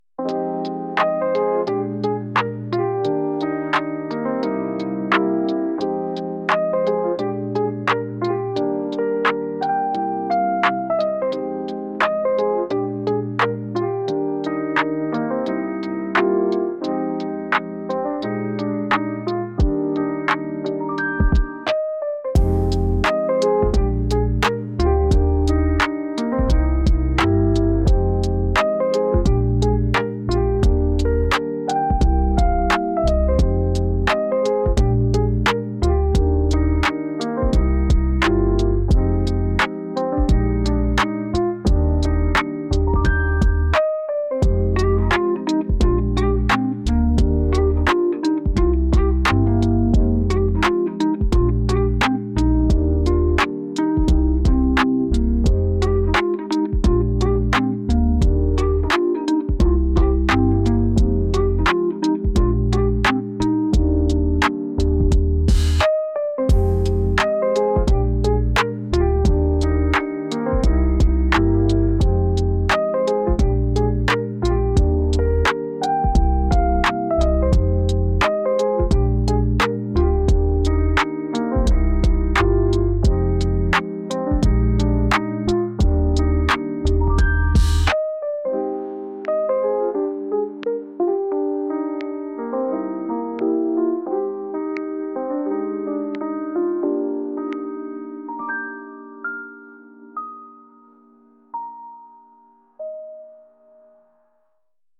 ゆったりするようなピアノ曲です。